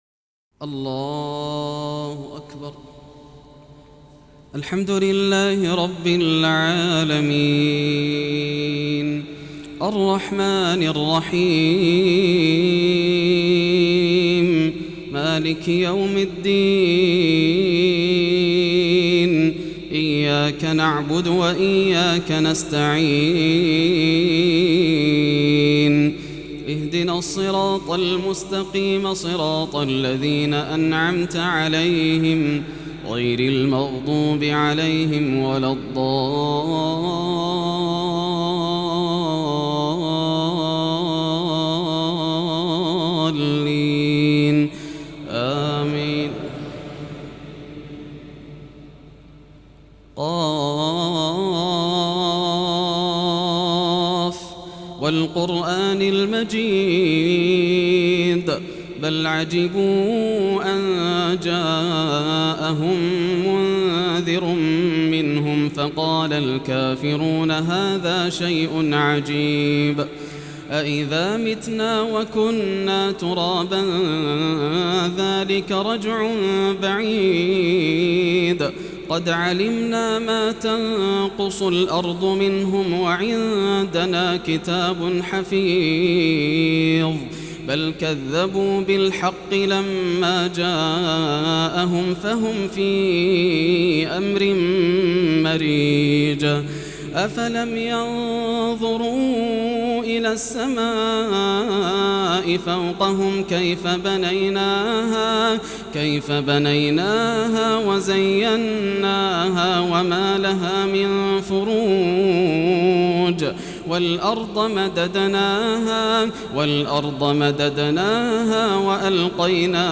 تراويح ليلة 13 رمضان 1432هـ من مسجد جابر العلي في دولة الكويت > الليالي الكاملة > رمضان 1432 هـ > التراويح - تلاوات ياسر الدوسري